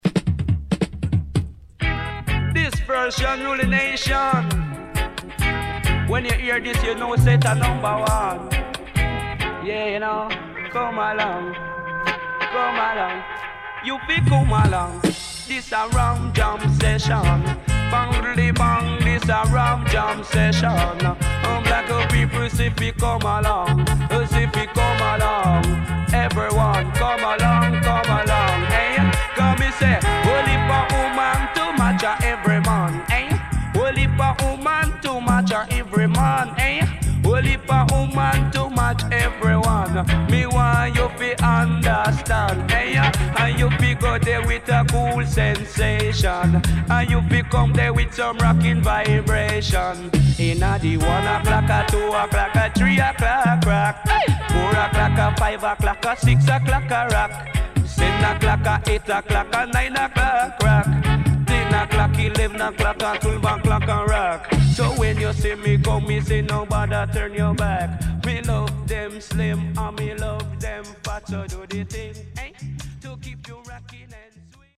HOME > REISSUE [REGGAE / ROOTS]
Early 80's Killer Vocal & Deejay.W-Side Good